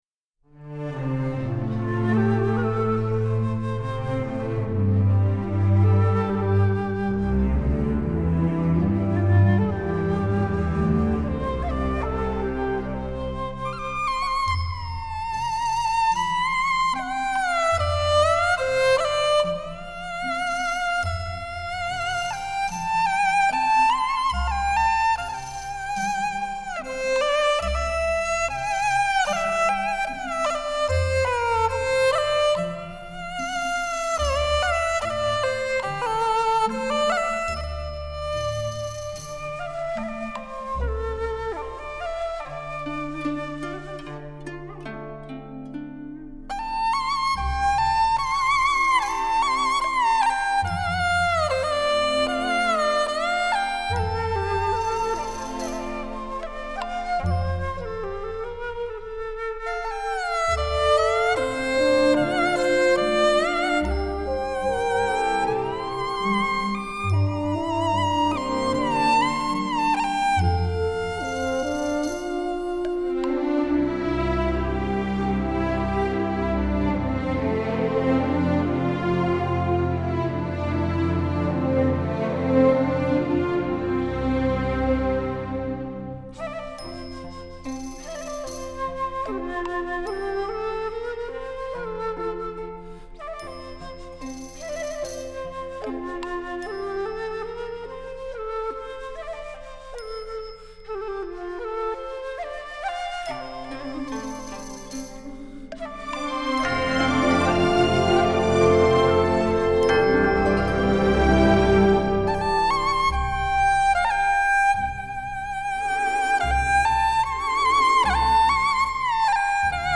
★  高規格、高取樣的 SACD 版本，不但質感與細膩度大幅提升，動態也更龐大、音場更顯開闊！